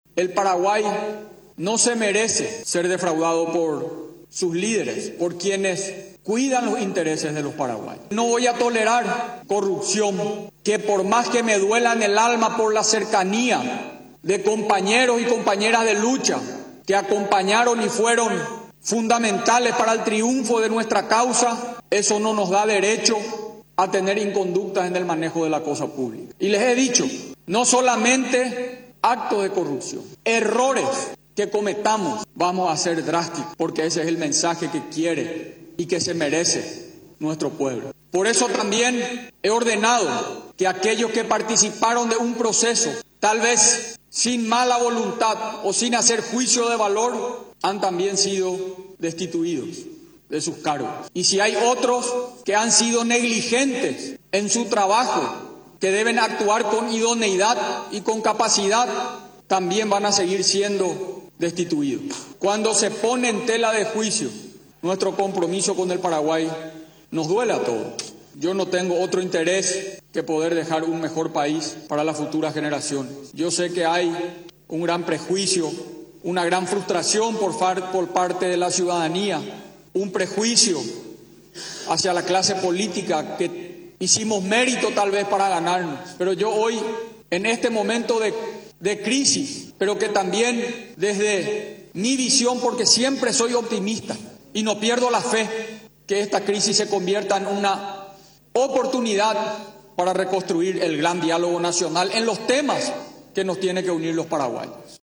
Recordó a la ciudadanía, durante el mensaje en Palacio de Gobierno, que ha ordenado que todos los que han actuado con negligencia y sin idoneidad sean destituidos de sus cargos.